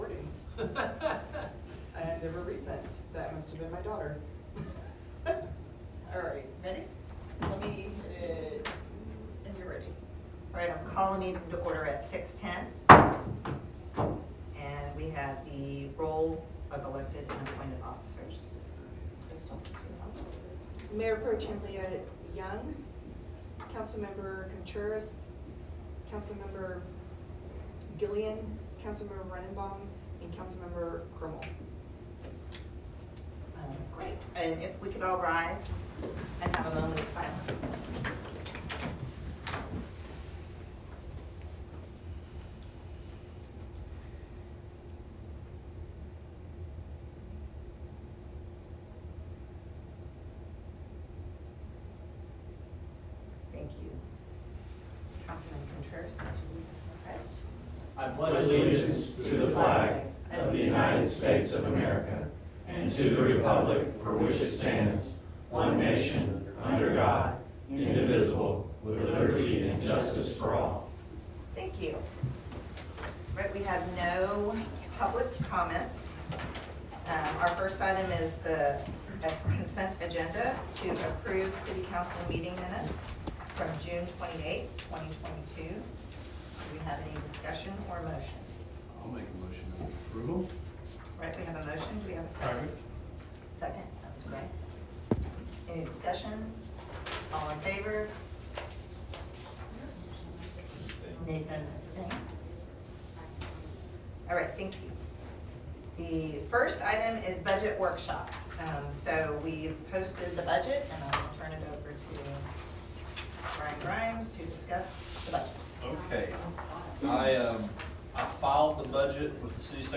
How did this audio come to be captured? However, Willow Park Civics was in attendance and is sharing the audio recording of the full council meeting.